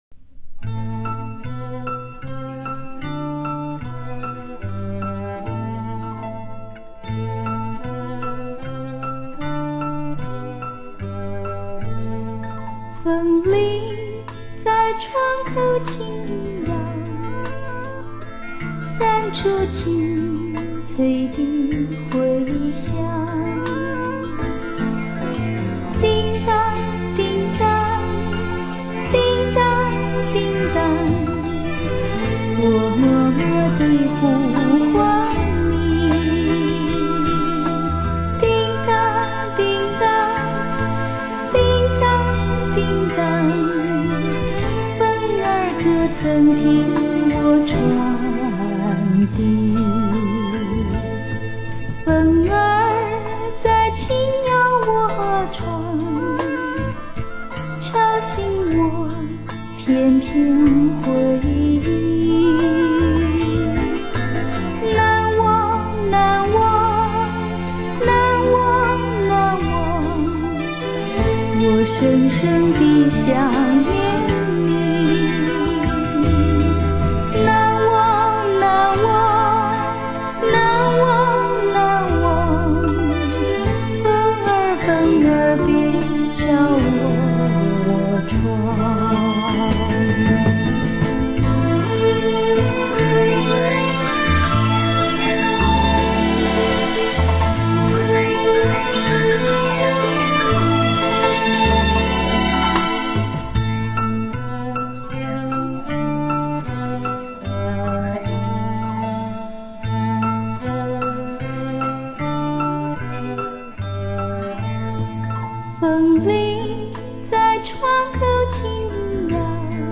校园情歌
这回她用甜美的声线，再献校园歌曲